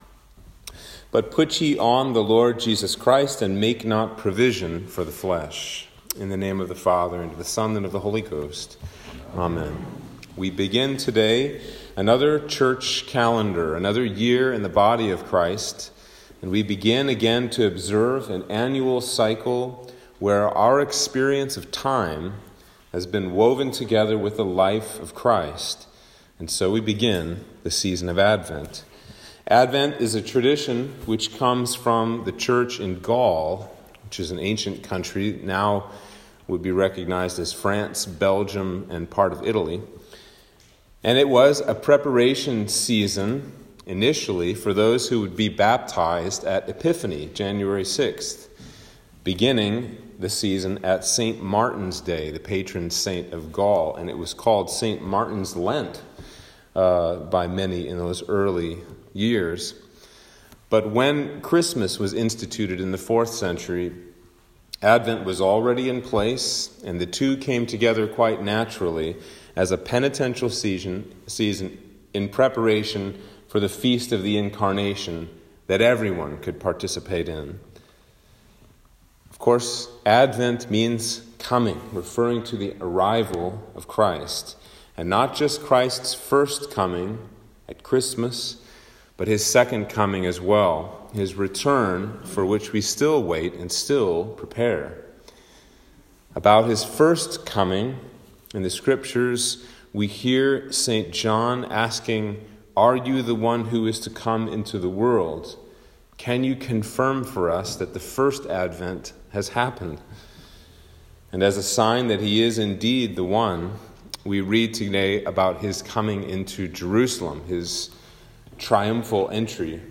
Sermon for Advent 1 - 2021